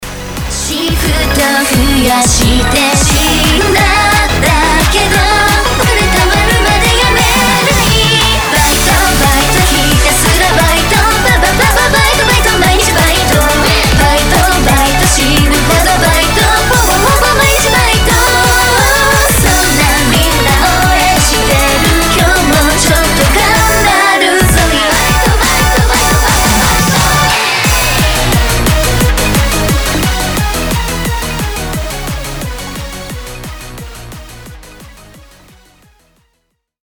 EUROBEAT